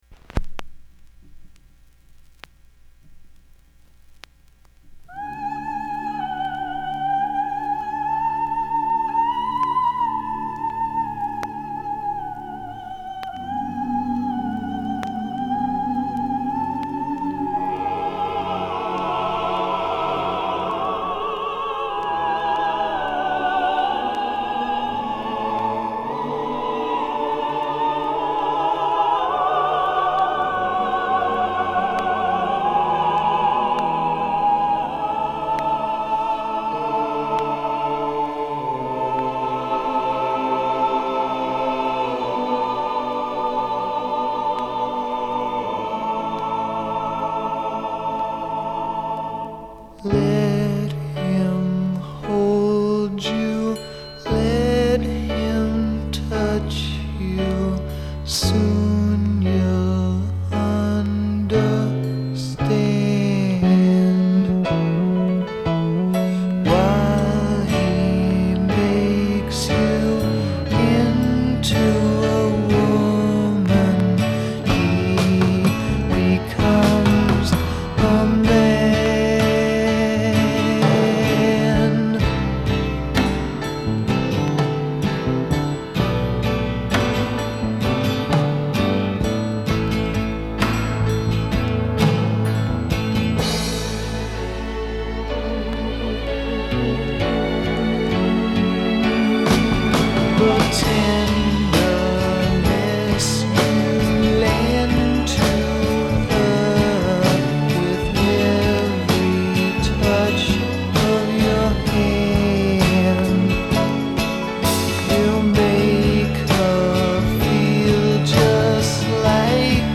packs alot of ballad in it’s three minutes
ends with some pretty tasty guitar work